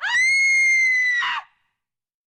scream1.wav